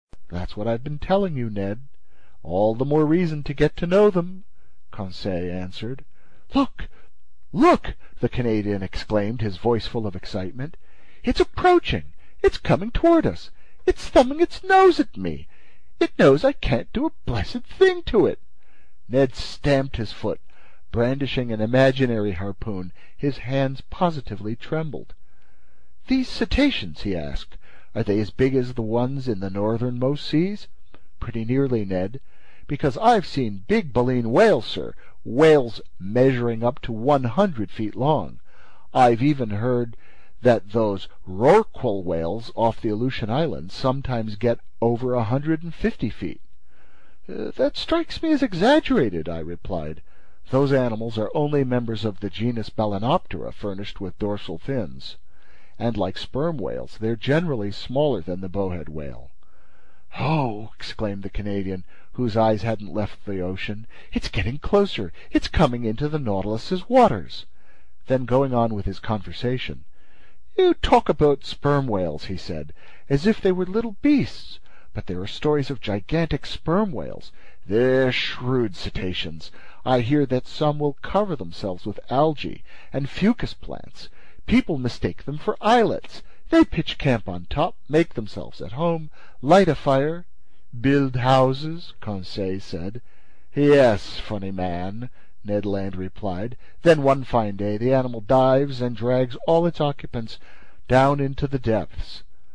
英语听书《海底两万里》第425期 第26章 大头鲸和长须鲸(6) 听力文件下载—在线英语听力室